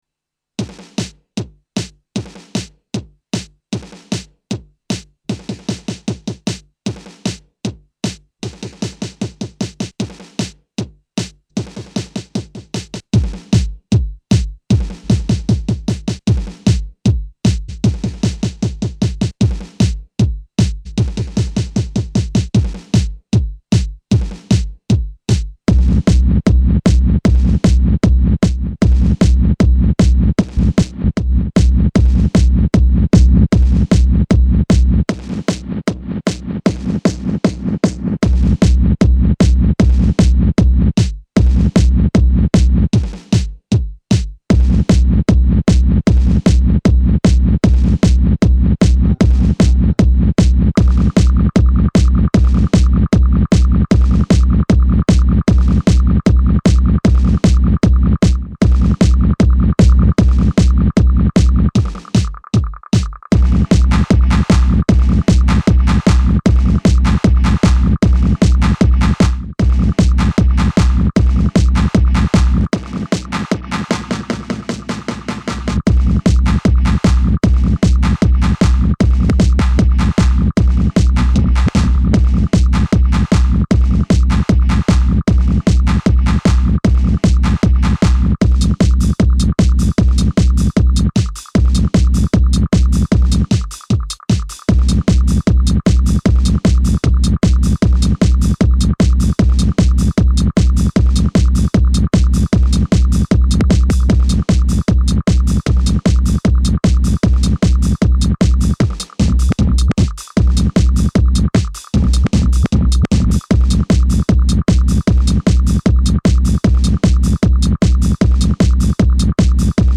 live korg techno Descàrregues i reproduccions